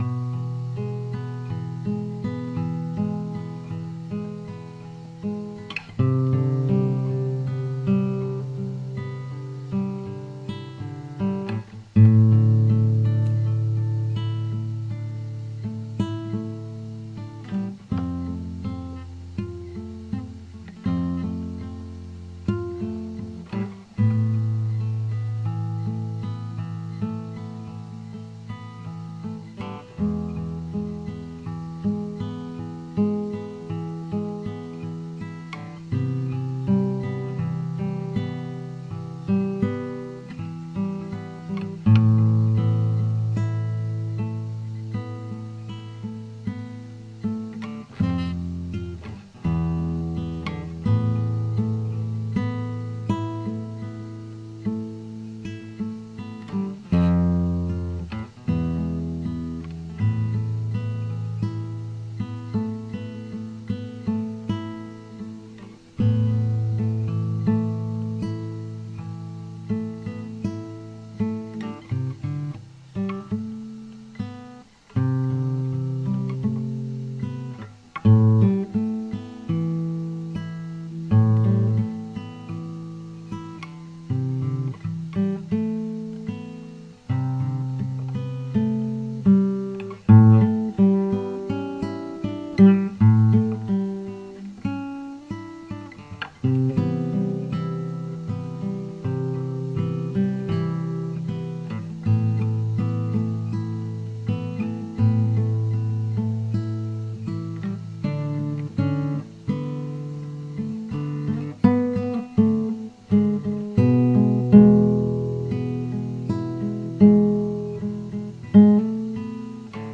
冰冷的原声吉他2
标签： 80 bpm Acoustic Loops Guitar Acoustic Loops 1.51 MB wav Key : C
声道立体声